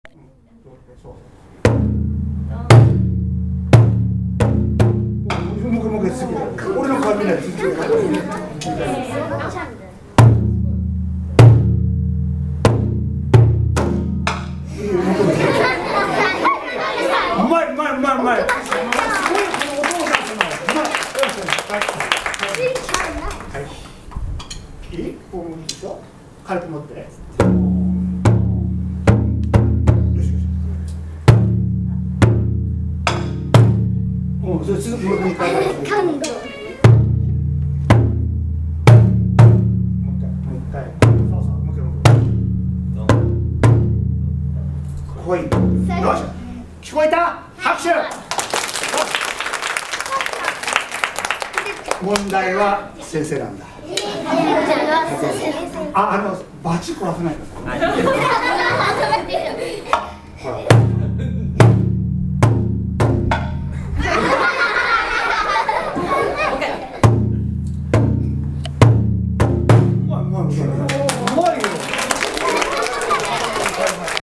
４年生落語教室
生の落語を鑑賞し，太鼓や小話の体験を通して日本の伝統芸能への理解を深めました。